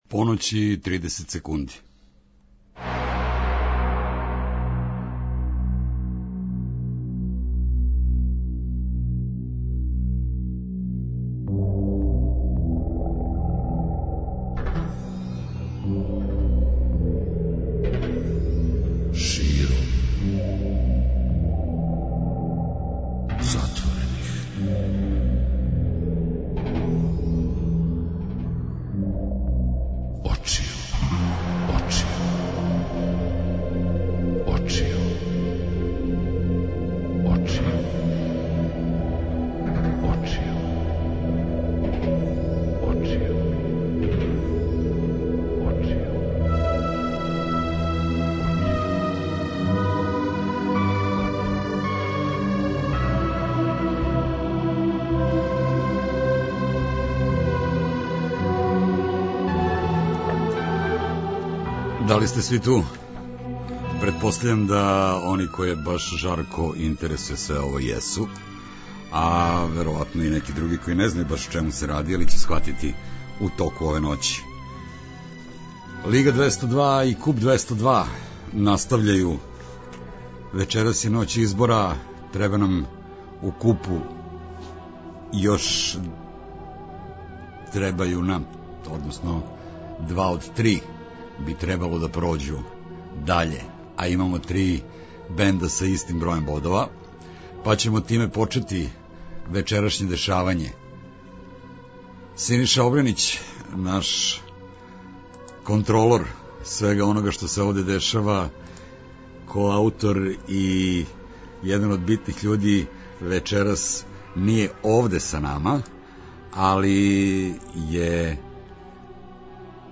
Лига 202 и Куп 202 - спој добре рок музике, спортског узбуђења и навијачких страсти.